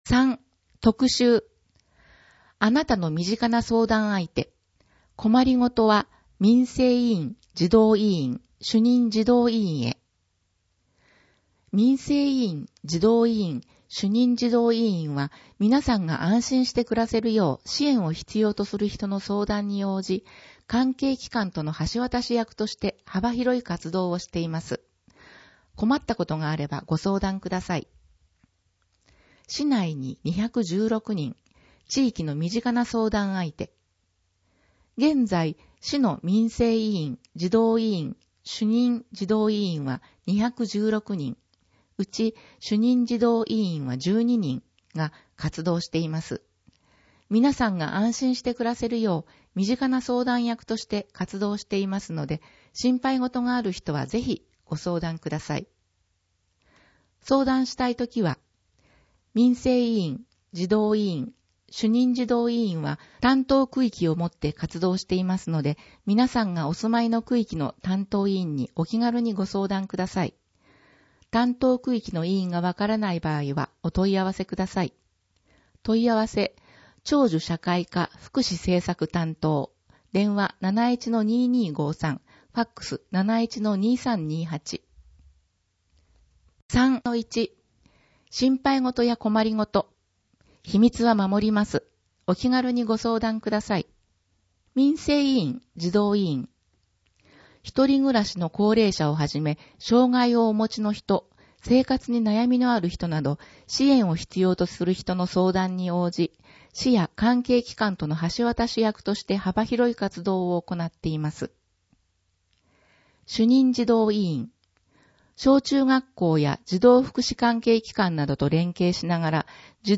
広報あづみの朗読版291号（令和元年5月22日発行)
「広報あづみの」を音声でご利用いただけます。